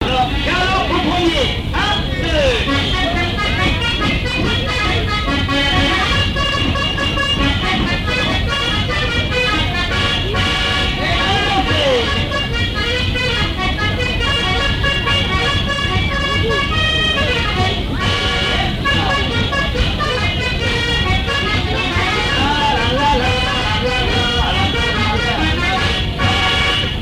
danse : quadrille : galop
lors d'une kermesse
Pièce musicale inédite